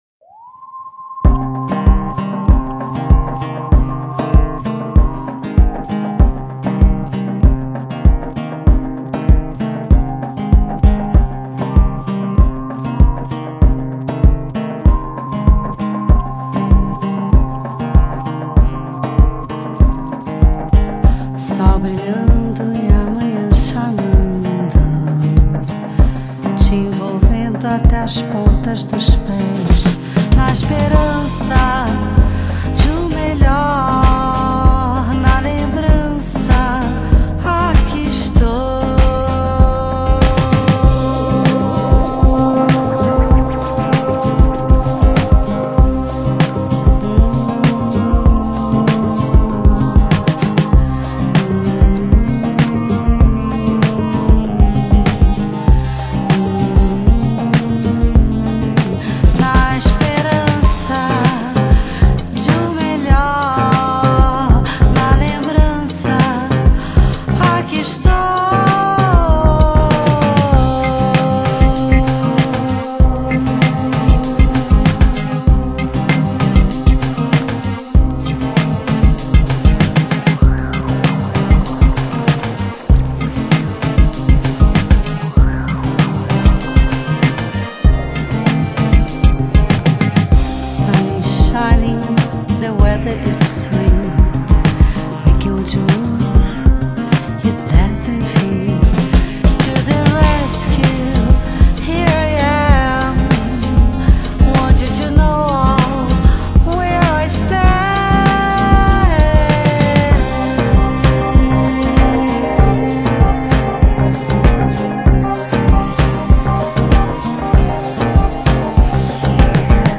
Blues And Jazz Para Ouvir: Clik na Musica.